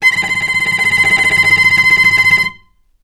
vc_trm-C6-mf.aif